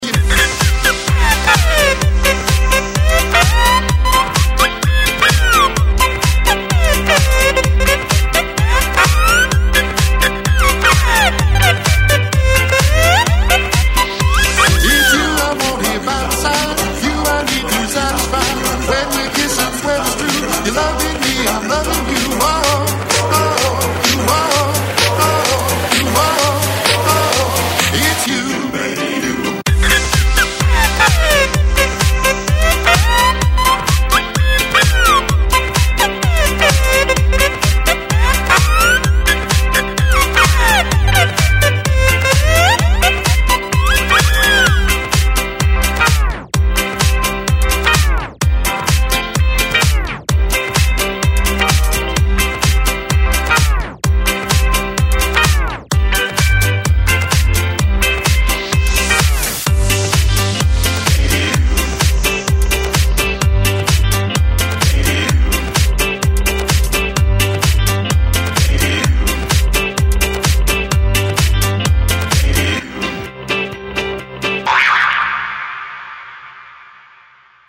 • Качество: 128, Stereo
Electronic